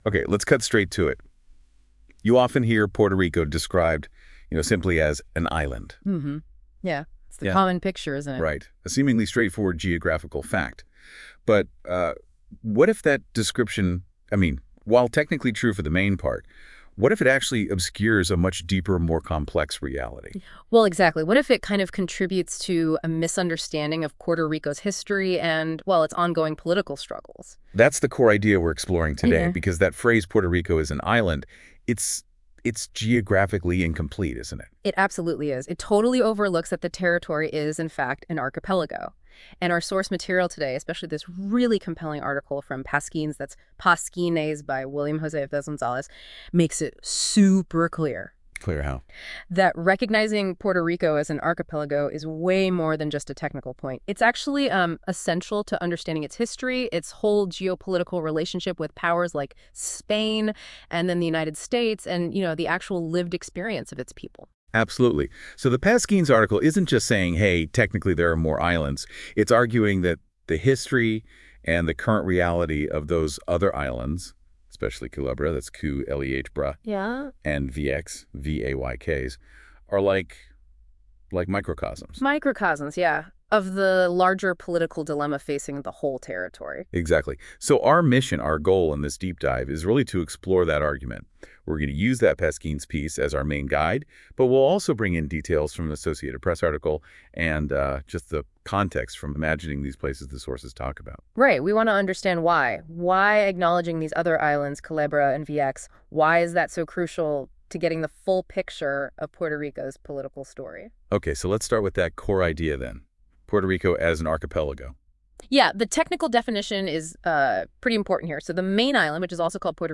This episode was generated using Google NotebookLM and reviewed for quality and accuracy by a Pasquines editor.